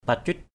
/pa-cʊit/
pacuit.mp3